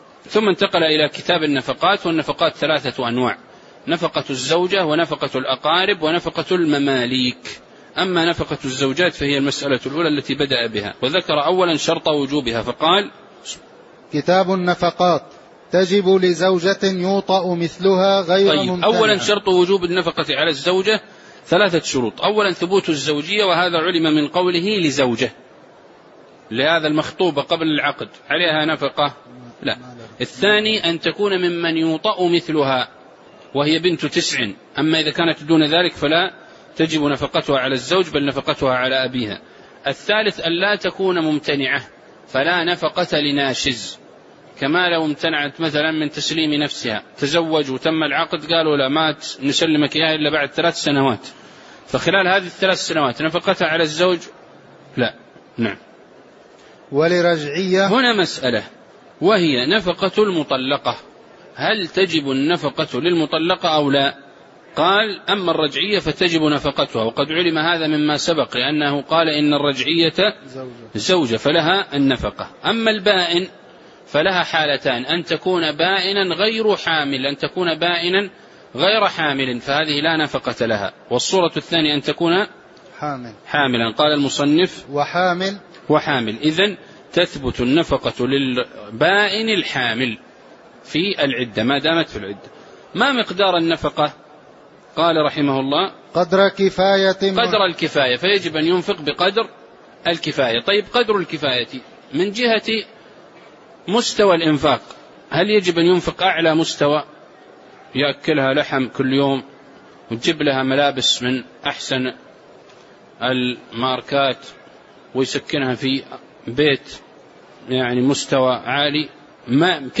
تاريخ النشر ٢١ شوال ١٤٣٩ هـ المكان: المسجد النبوي الشيخ